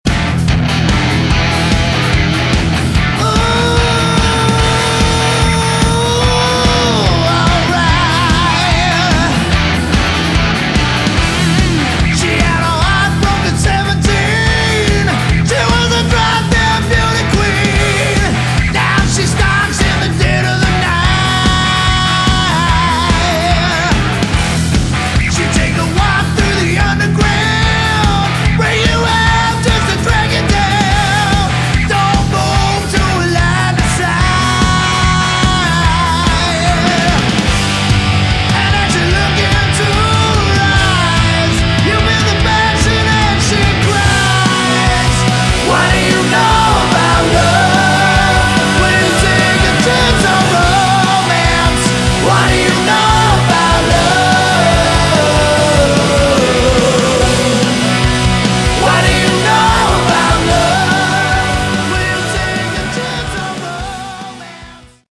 Category: AOR / Melodic Rock
lead vocals
guitars, backing vocals
keyboards, backing vocals
bass, backing vocals
drums, backing vocals